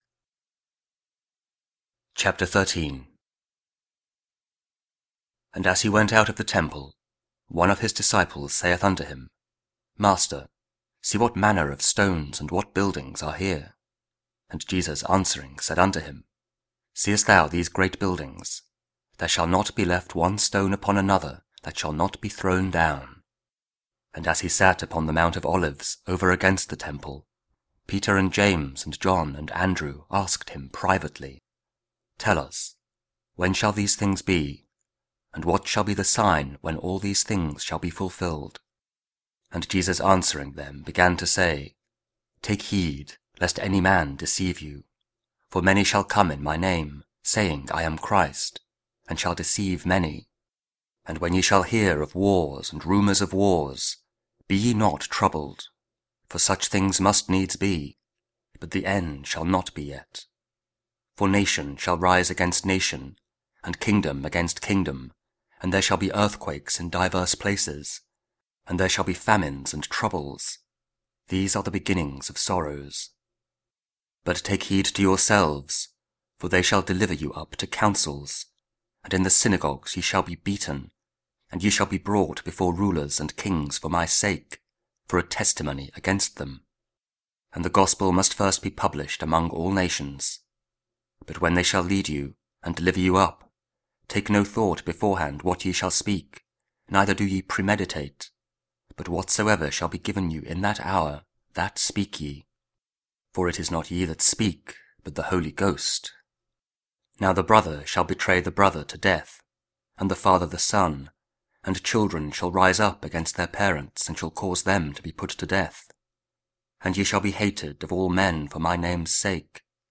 Mark 13: 24-32 – 33rd Sunday Year B (Audio Bible KJV, Spoken Word)